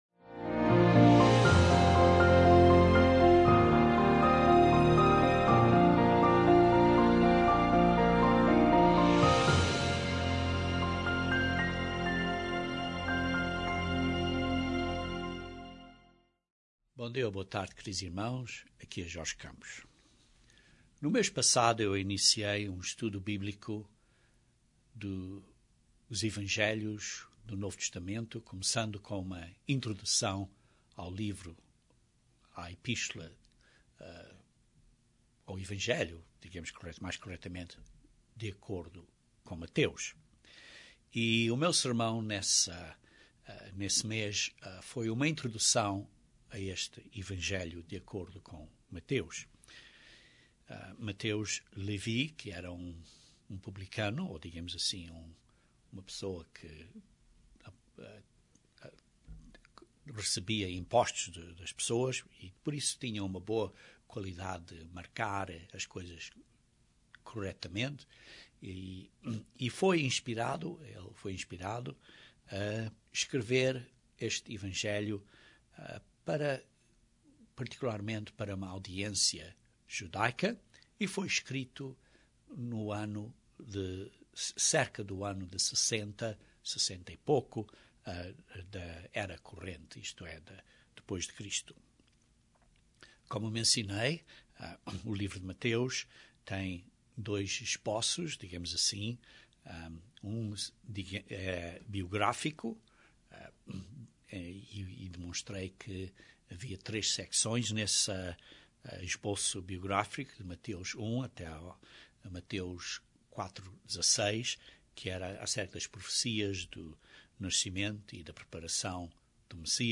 Estudo Bíblico de Mateus 1